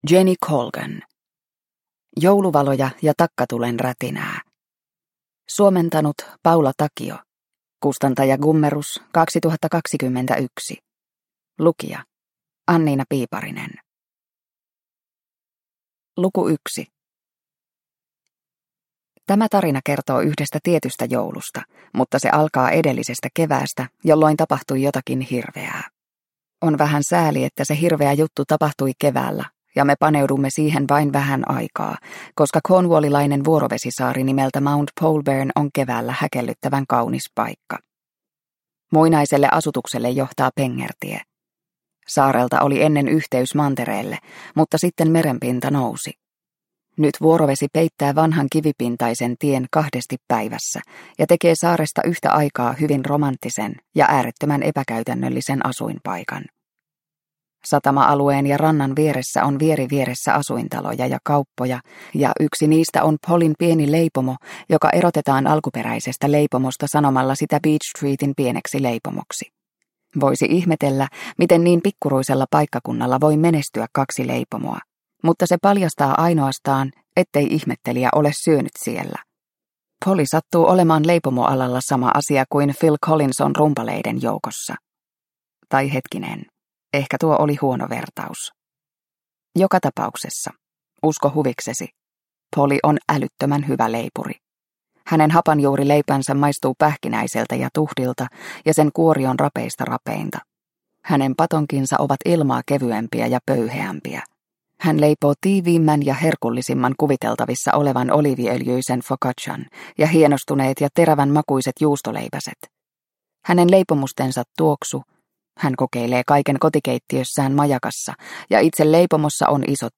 Jouluvaloja ja takkatulen rätinää – Ljudbok – Laddas ner